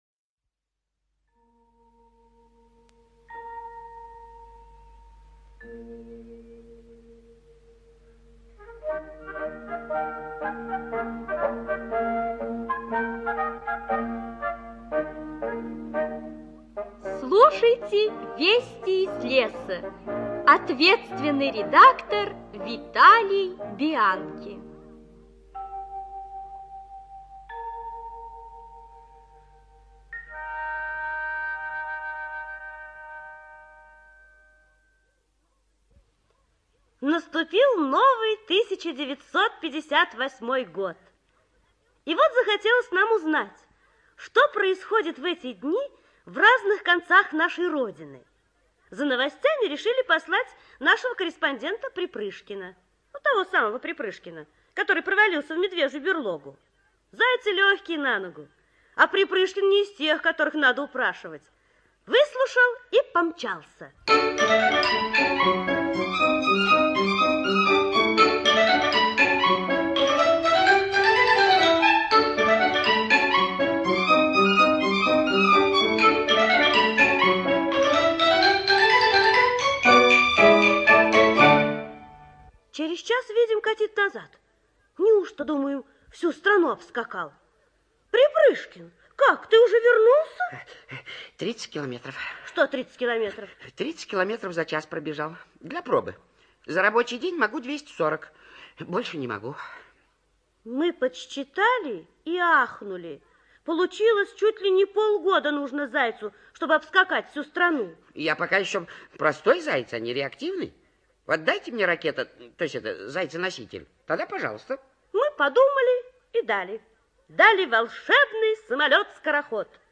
ЖанрДетская литература, Природа и животные, Радиопрограммы